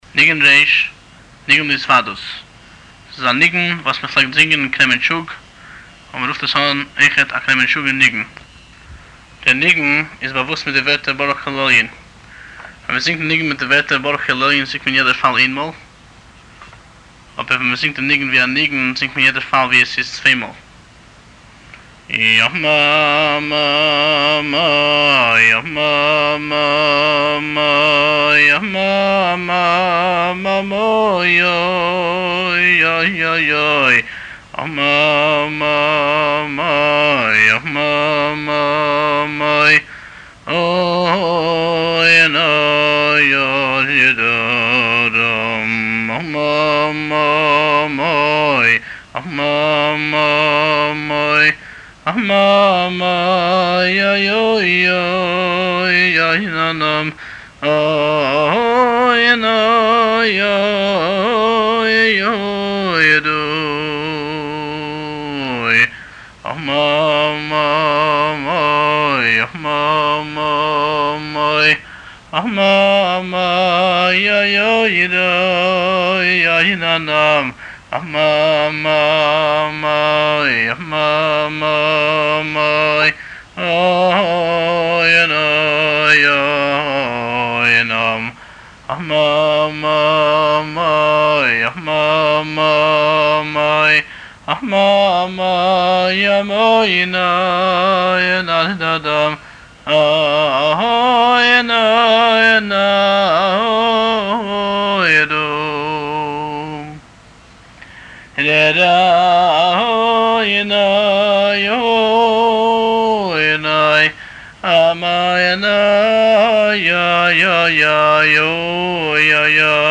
לשמיעת הניגון מאת הבעל-מנגן